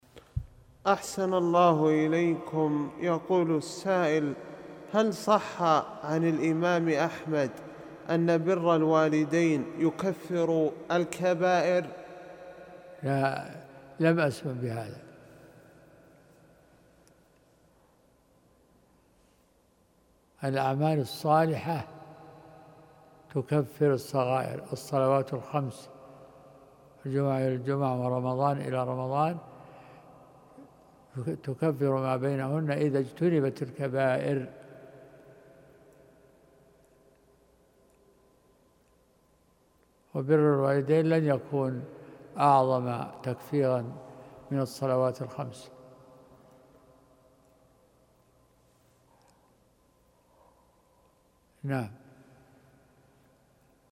فتاوى